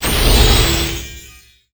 Magic_SpellShield08.wav